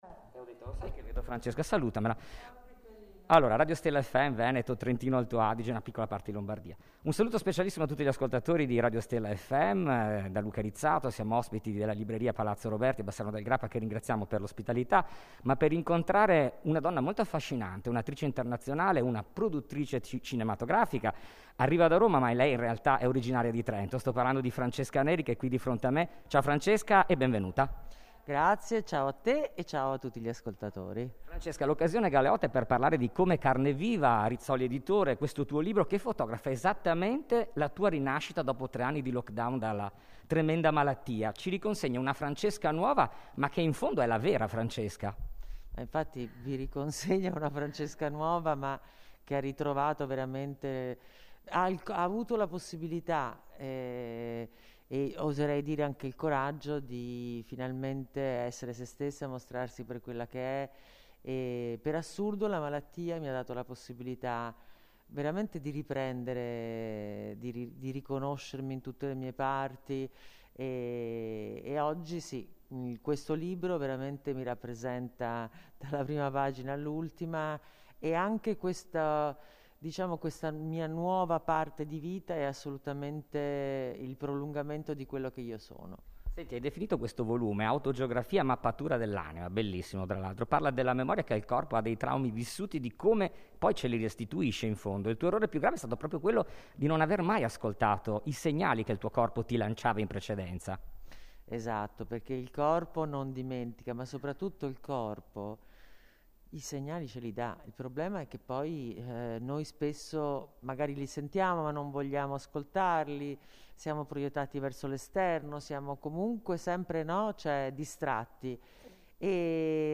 Intervista esclusiva dell’inviato per Stella FM a Francesca Neri.